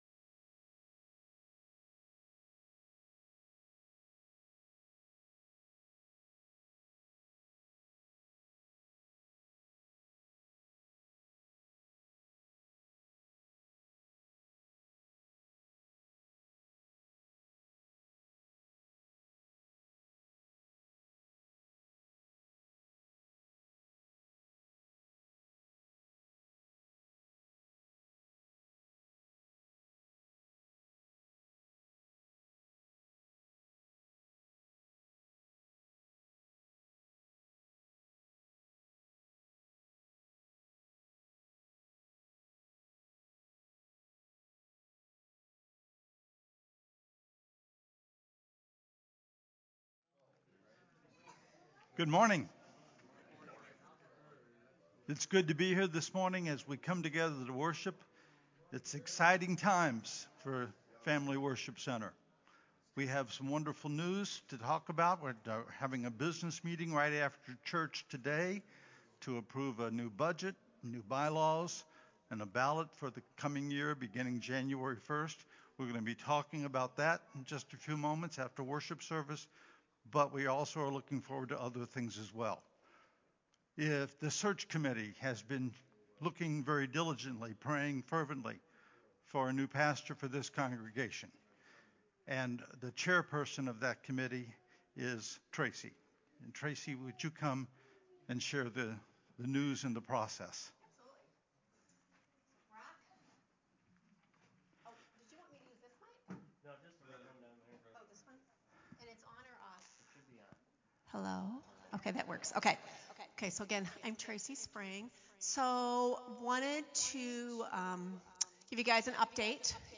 09 Oct 2022 Worship